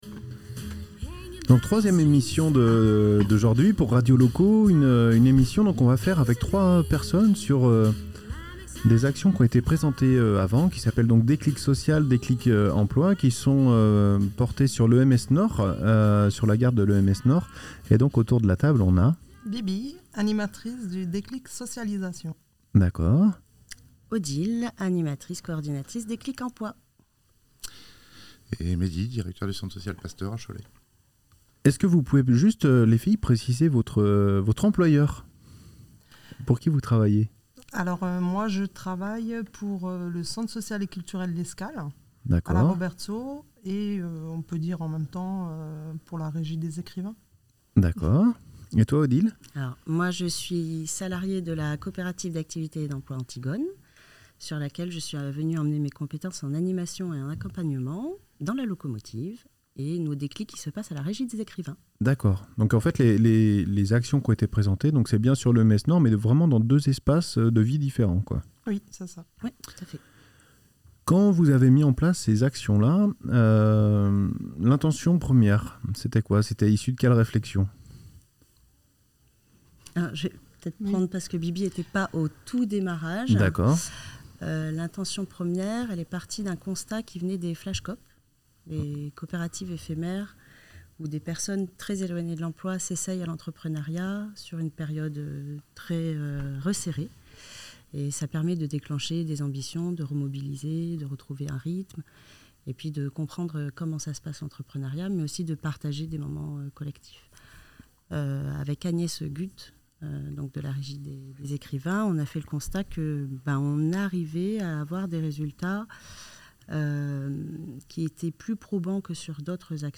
Ce podcast a été réalisé dans le cadre du programme La Locomotive. Il s'agit d'un échange entre membres du consortium "La Locomotive" porté par la Régie des écrivains.